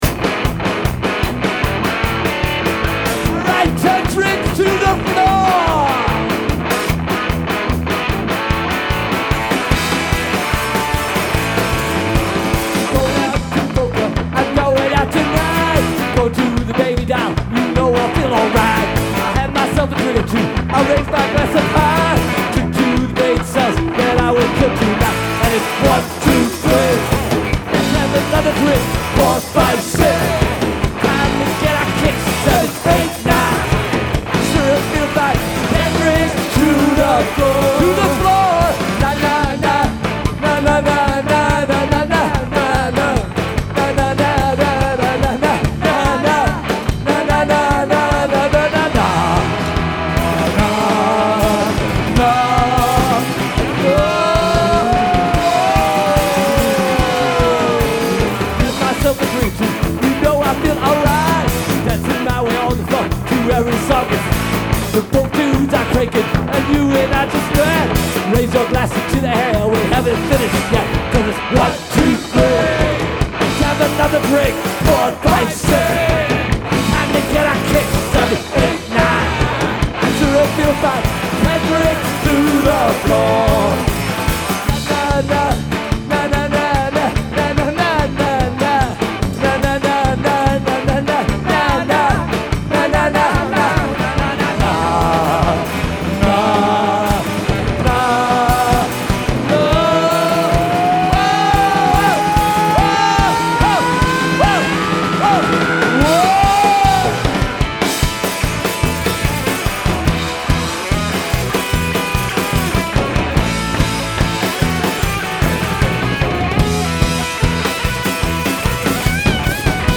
punk/polka band